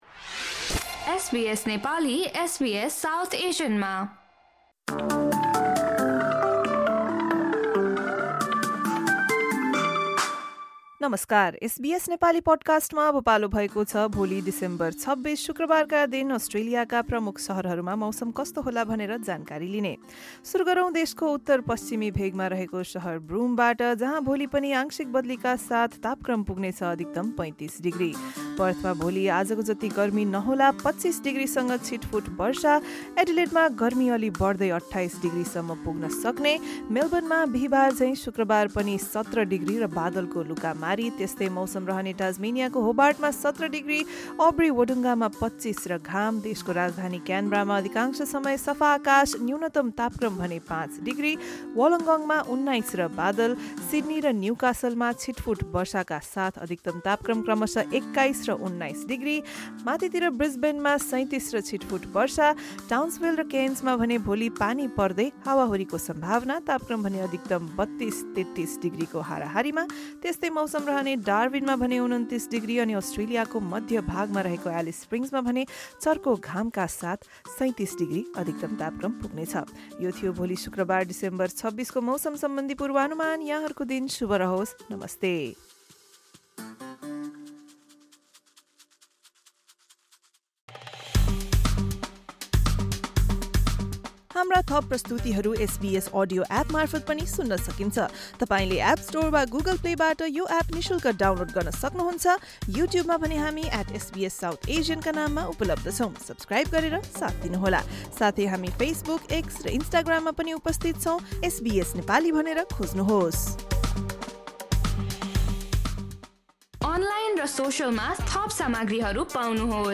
Weather update for major cities across Australia in Nepali. This update features tomorrow’s forecast for the following cities: Broome, Perth, Adelaide, Melbourne, Hobart, Albury-Wodonga, Sydney, Newcastle, Brisbane, Townsville, Cairns, Darwin and Alice Springs.